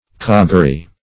coggery - definition of coggery - synonyms, pronunciation, spelling from Free Dictionary Search Result for " coggery" : The Collaborative International Dictionary of English v.0.48: Coggery \Cog"ger*y\, n. Trick; deception.
coggery.mp3